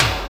MANIC SNR.wav